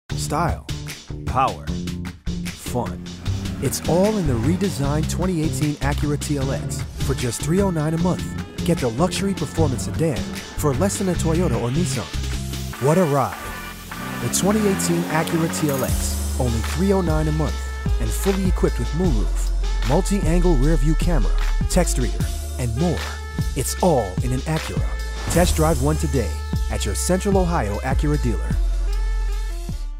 Young Adult, Adult
Has Own Studio
cool
quirky
warm/friendly